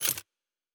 Weapon 06 Foley 2.wav